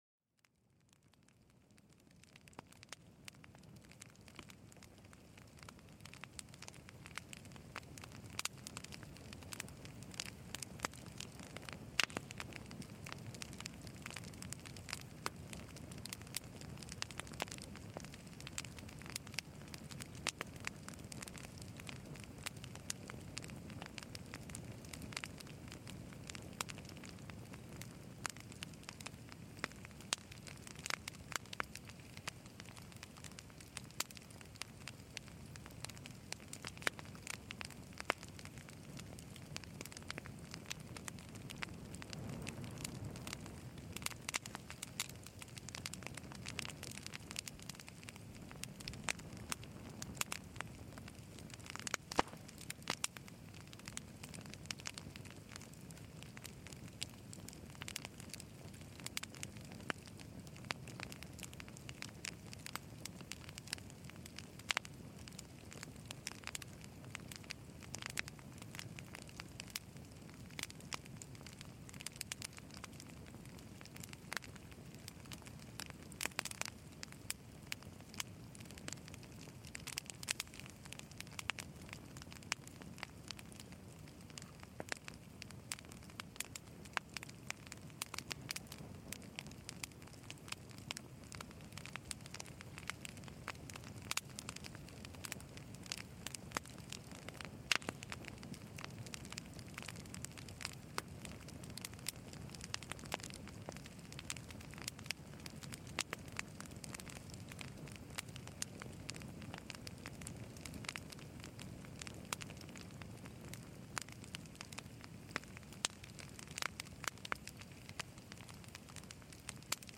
Sonidos de Hoguera: Serenidad en el Crepitar de las Llamas
Relájate escuchando el suave crepitar de una hoguera. Los chasquidos de las ramas y el calor imaginario de las llamas evocan una tranquila noche bajo las estrellas.